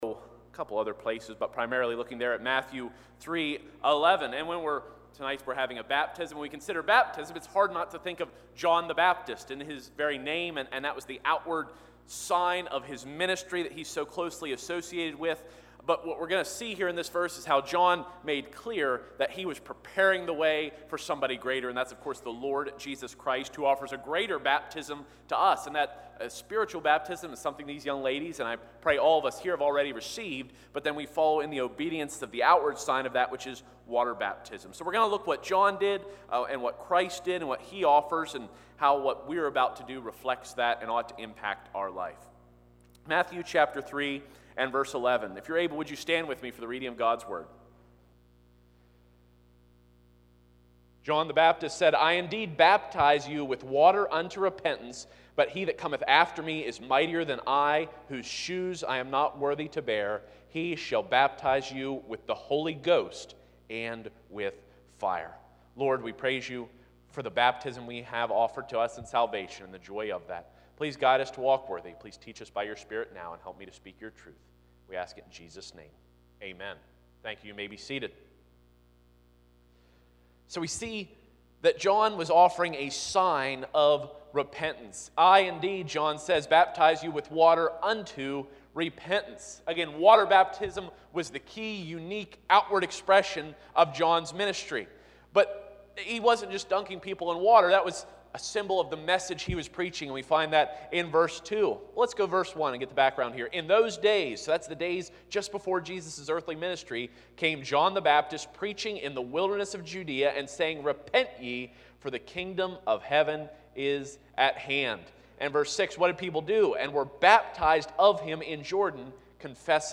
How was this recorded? Matthew 3:11 Service Type: Sunday 6:00PM I. The Sign of Repentance II.